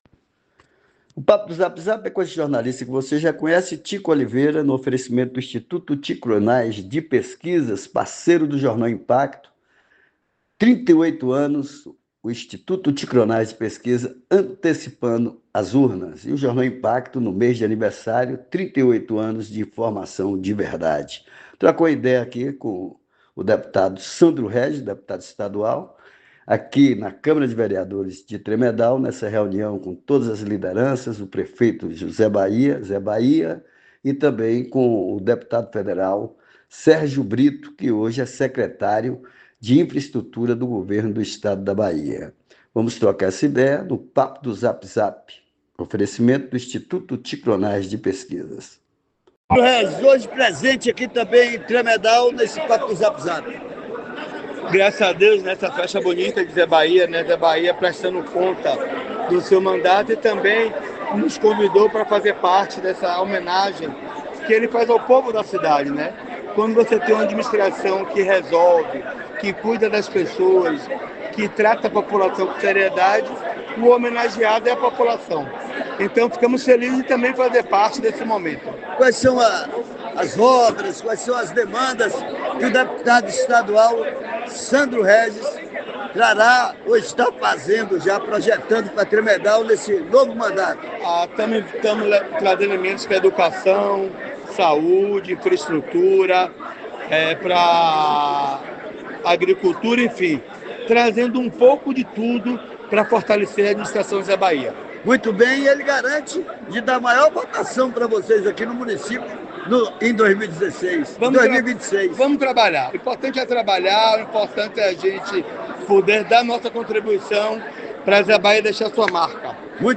Os deputados Sandro Regis, Sergio Brito e o prefeito Dr.Zé Bahia trocaram ideia no papo do zap zap
Participando do inicio da festa junina no município de Tremedal, o deputado estadual Sandro Régis e o deputado federal Sérgio Brito, empolgou os presentes na reunião com lideranças do grupo politico liderado pelo prefeito Dr. Zé Bahia.